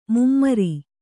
♪ mummari